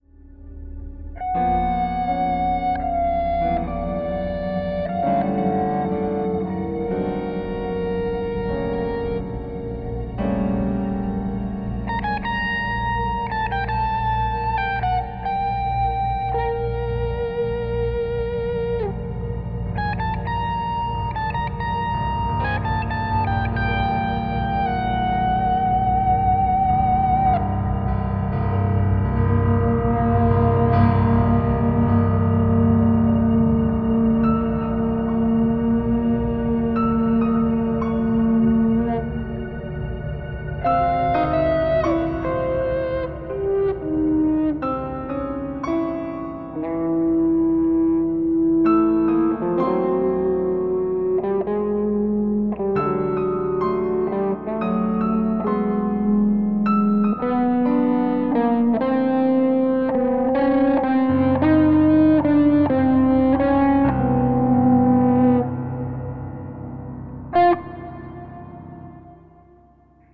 Electric guitar, Soundscapes, Live performance electronics